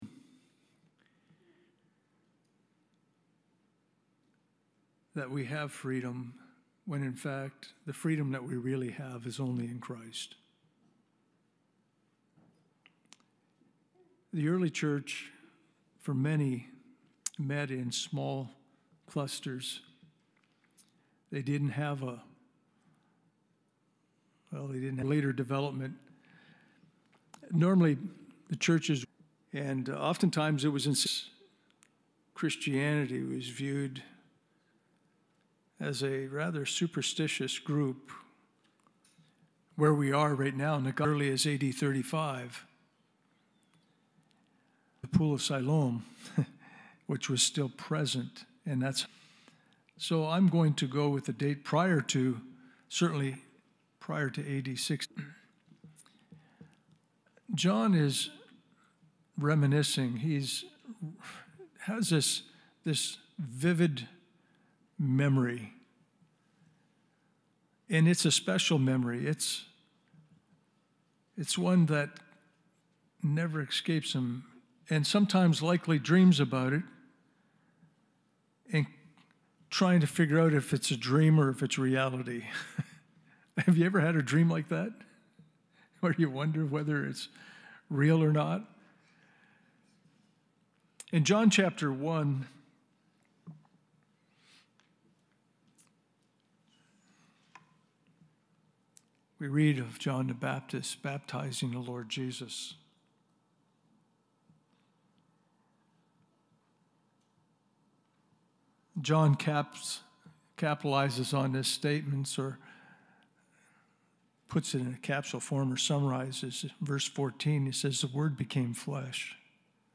Communion Passage: 1 John 1:1-10 Service Type: Sunday Morning « What Is The Wrath Of God That Is Being Revealed